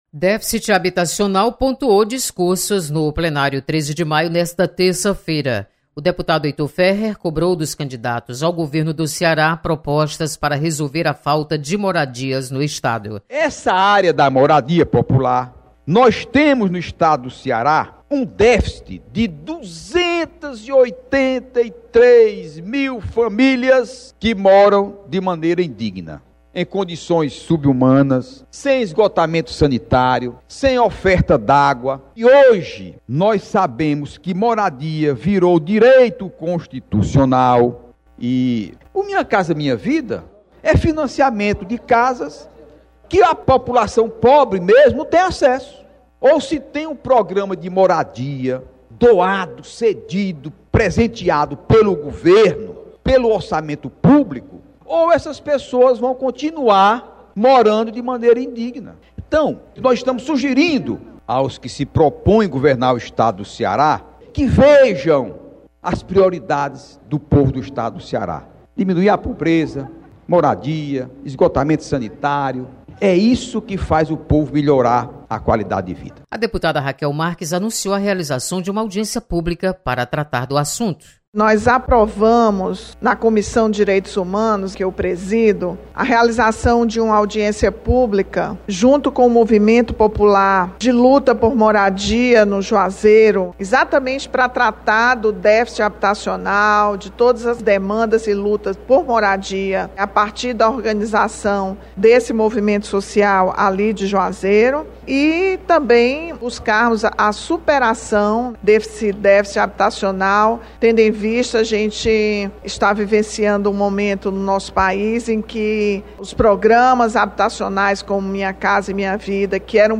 Deputada Rachel Marques propõem debate sobre déficit habitacional. Repórter